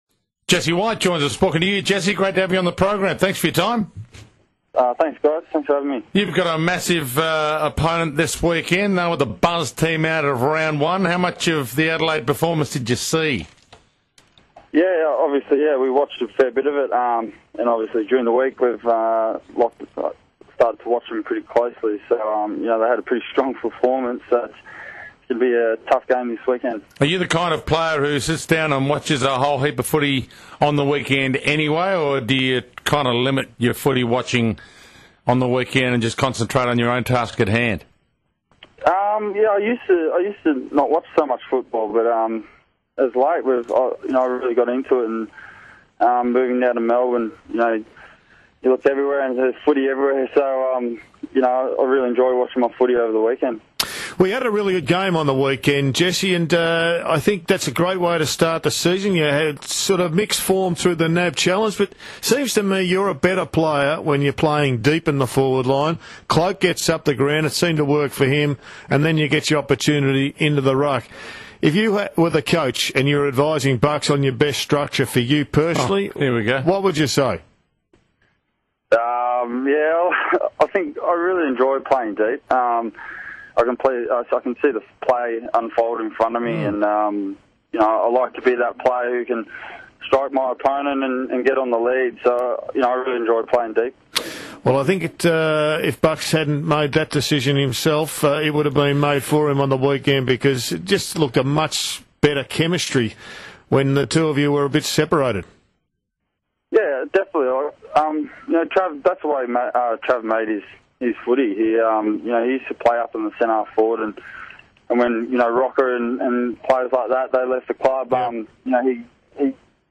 Listen to Collingwood forward Jesse White chat with the 3AW Sports Today team on Thursday evening ahead of his team's meeting with Adelaide on Saturday evening.